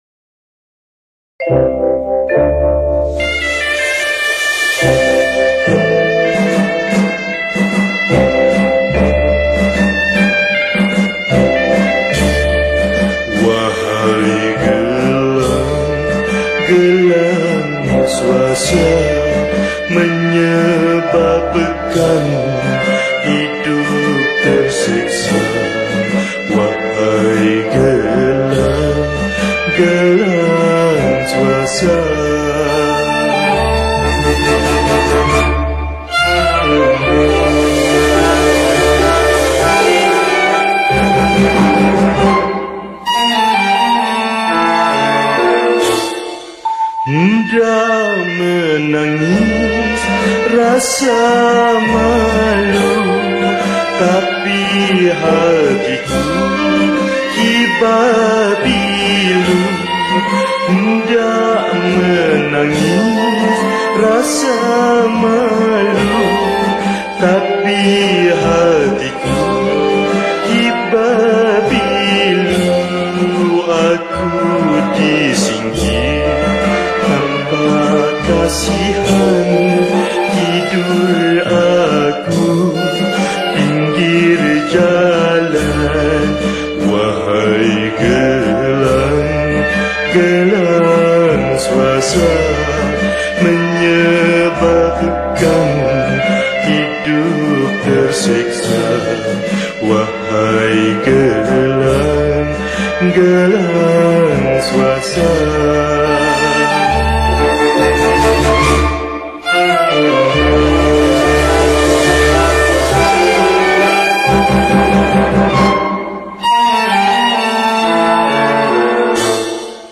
Chords : Dm